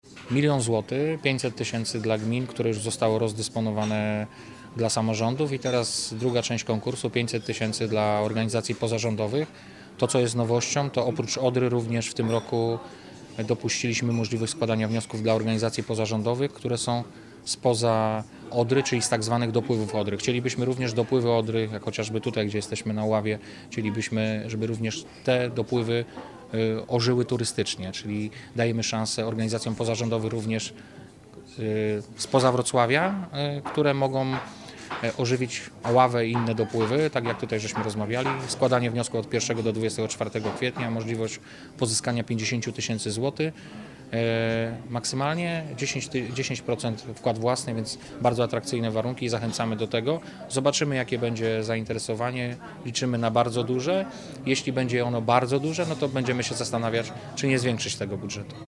Nowością jest możliwość składania wniosków przez podmioty działające nie tylko nad Odrą, ale także nad jej dopływami. Mówi Wojciech Bochnak, Wicemarszałek Województwa Dolnośląskiego.